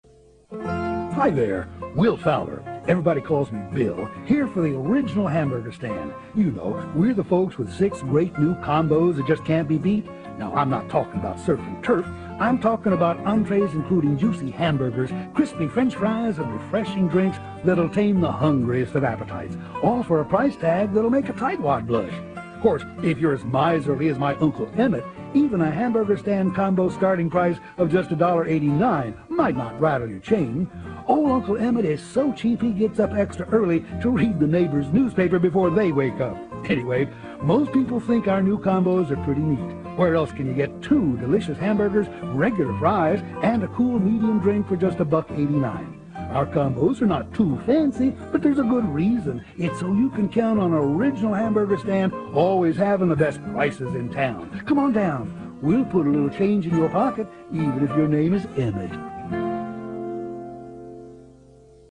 Various Radio Commercial Work